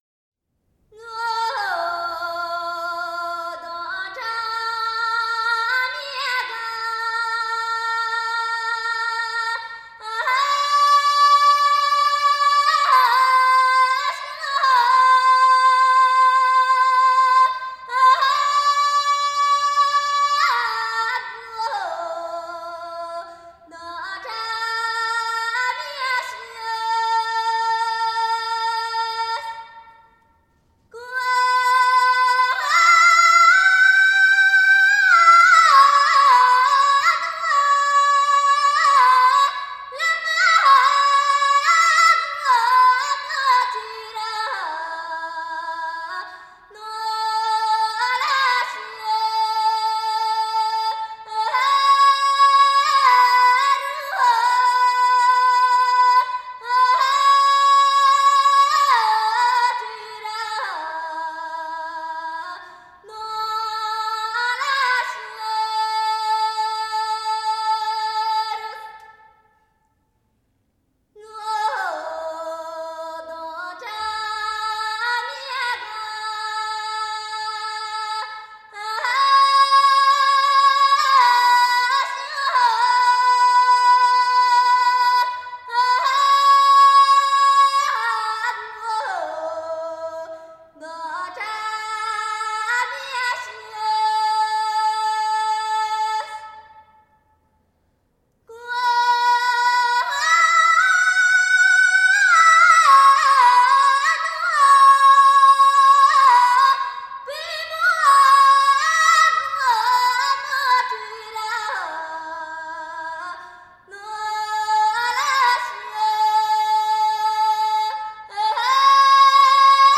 少数民族音乐系列
29首歌，旋律朴实悦耳，歌声高吭开怀，
充份展现厡野牧民和农村纯朴，直率奔放的民风。
都有嘹亮的歌声，一流的技巧。